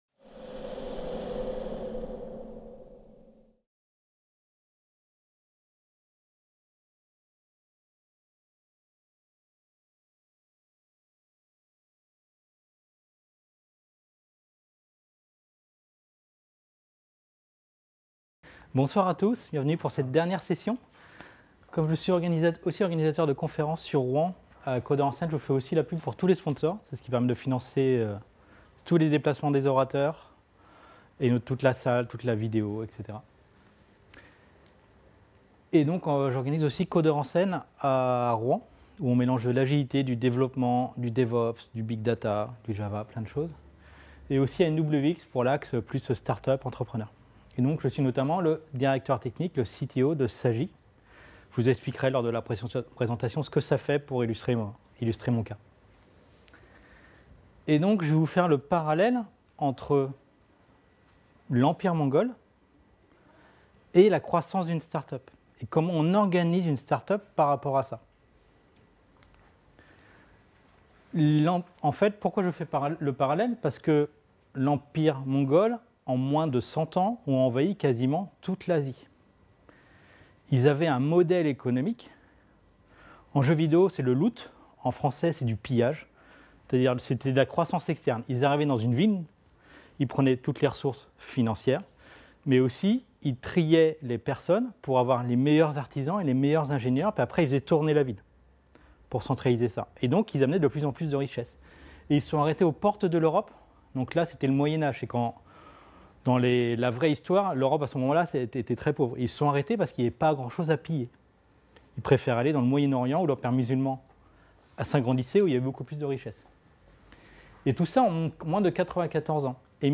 12 - Atelier 3 : Du Kanban pour ma startup (PA2017) | Canal U